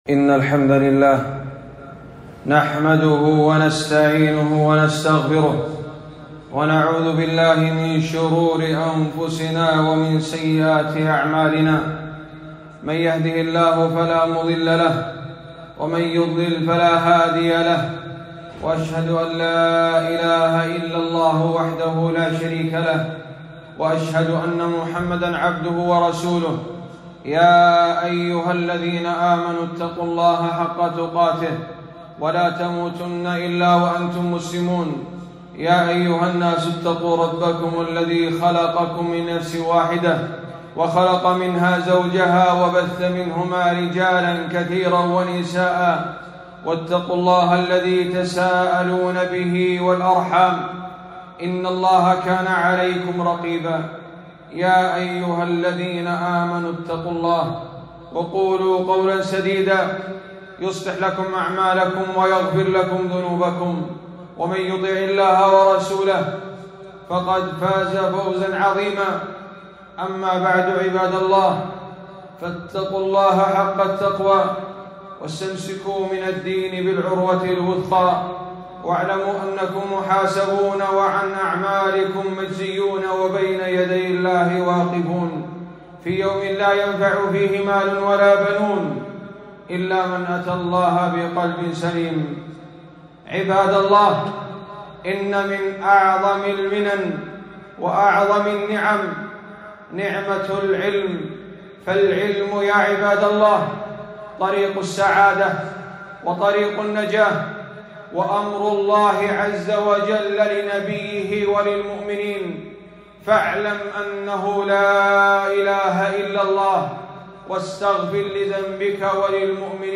خطبة - فضل العلم والعلماء - دروس الكويت